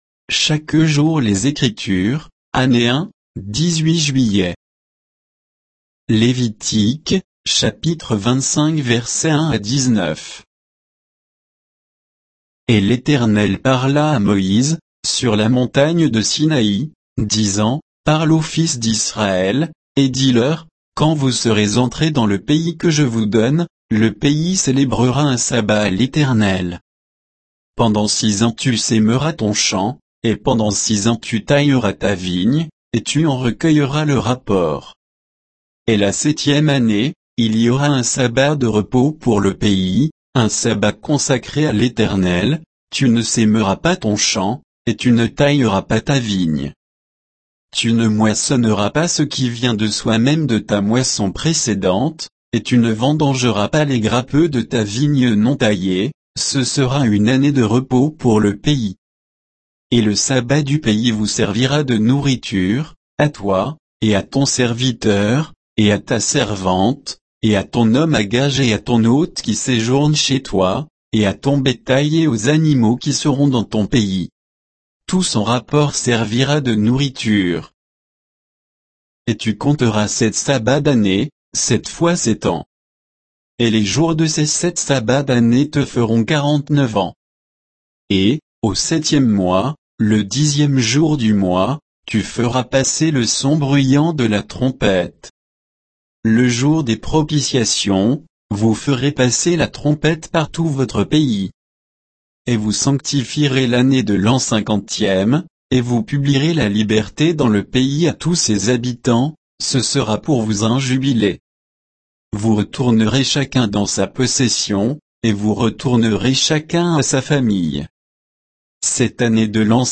Méditation quoditienne de Chaque jour les Écritures sur Lévitique 25